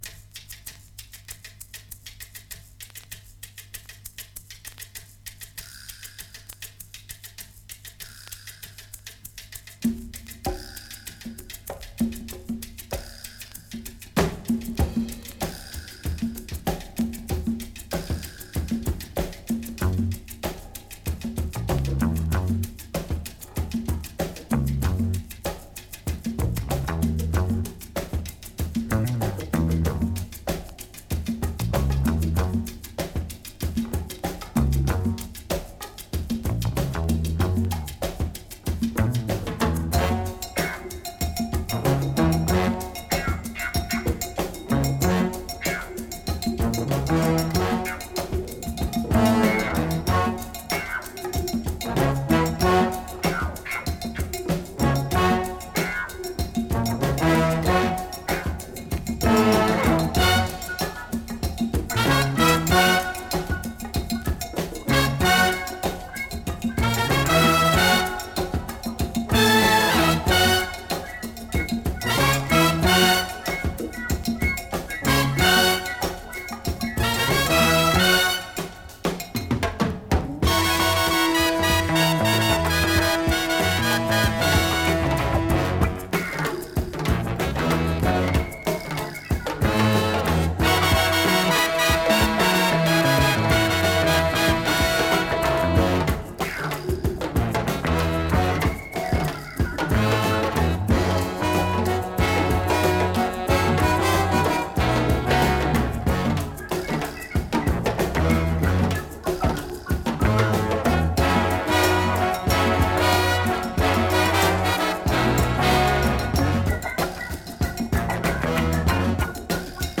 Heavy weight cinematic groove from UK !
Super breaks !